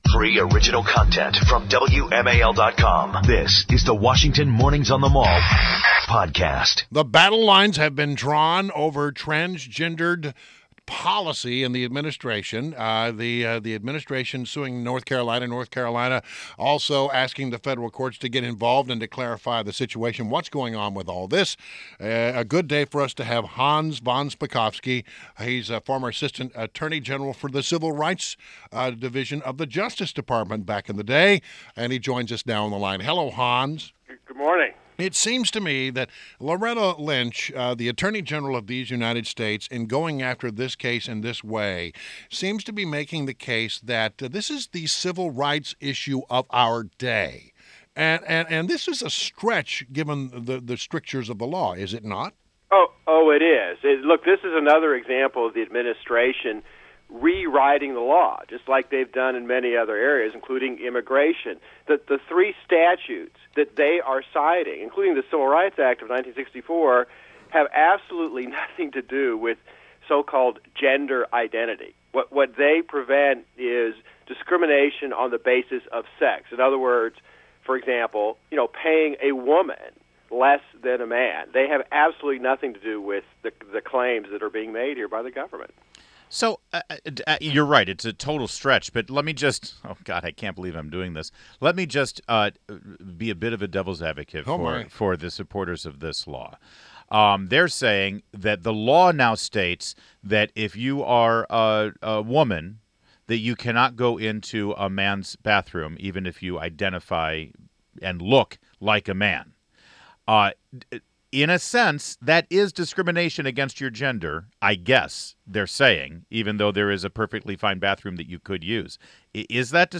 WMAL Interview: Hans Von Spakovsky 05.10.16
INTERVIEW - HANS VON SPAKOVSKY - a former counsel to the assistant attorney general for civil rights in the Justice Department, former commissioner for the Federal Election Commission from 2006 to 2007 and senior fellow at the Heritage Foundation